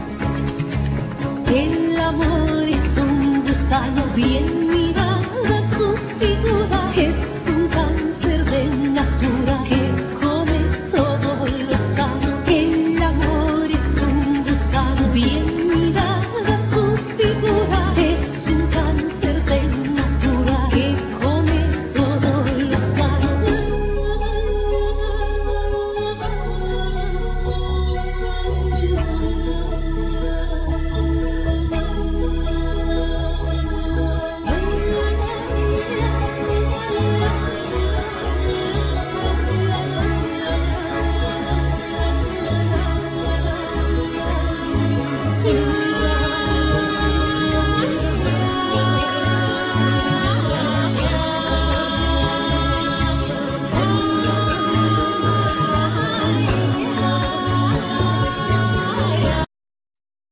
Voices
Guitars,Bouzouki,Dulcimer,Voices
Clarinet,Bass Clarinet,Saxophne
Piano